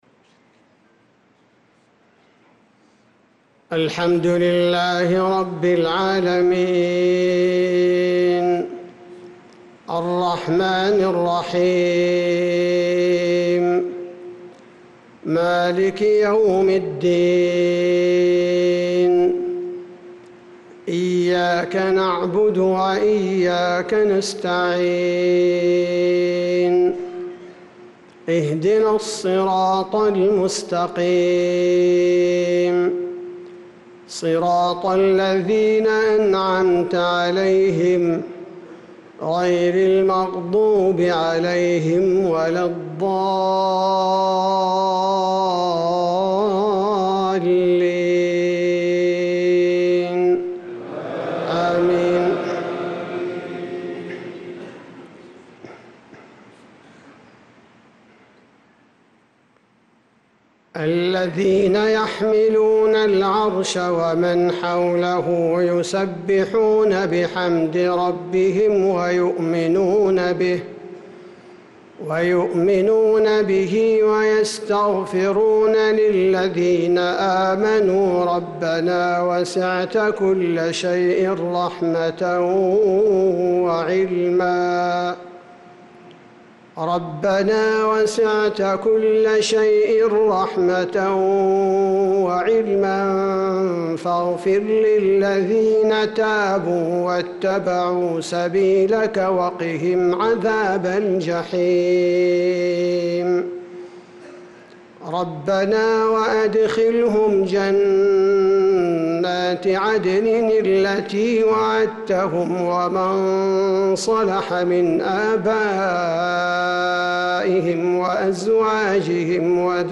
صلاة العشاء للقارئ عبدالباري الثبيتي 26 ربيع الأول 1446 هـ